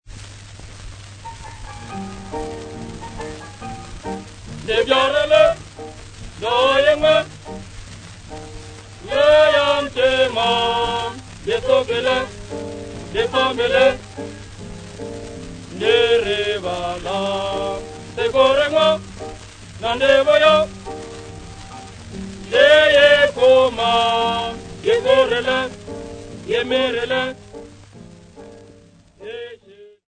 Popular music--Africa
sound recording-musical